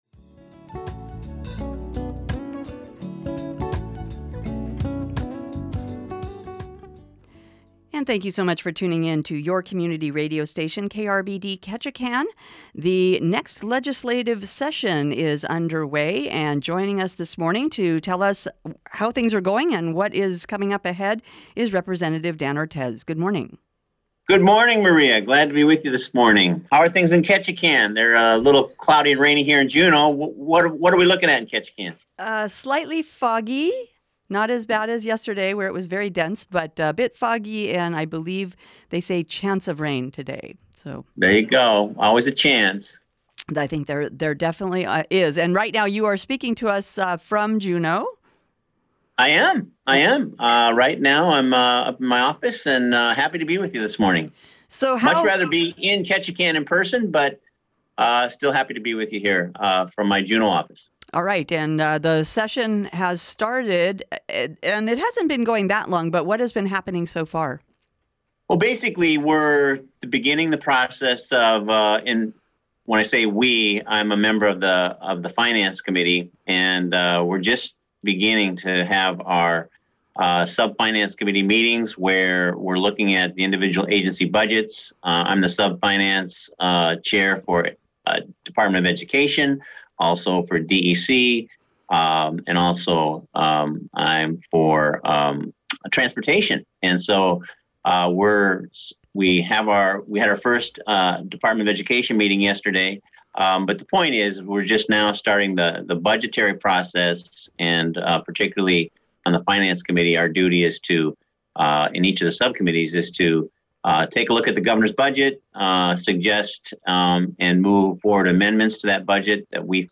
Independent Rep. Dan Ortiz of Ketchikan spoke with KRBD about the start of the session and his thoughts on the state’s ferry system.